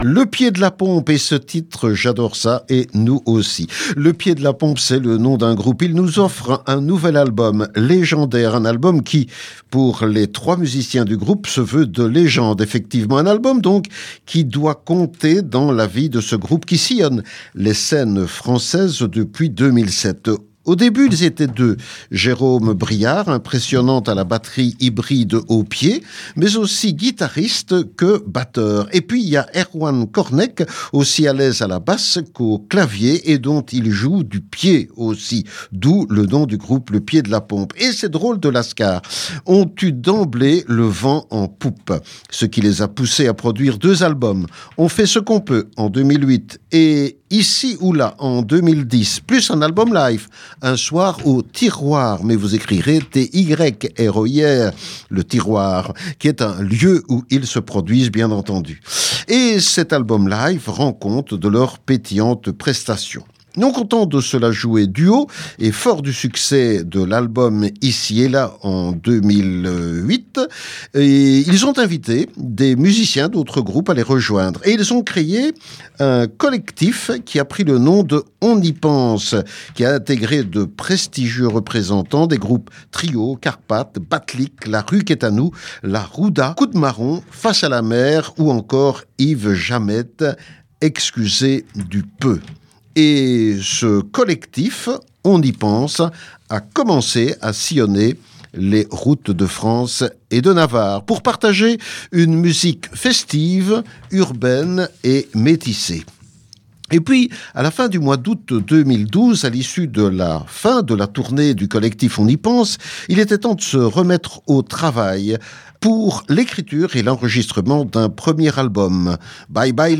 la basse, choeurs et claviers de «Type Taurus» aux pieds
choeurs et Theremin.